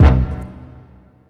Dre hit3.wav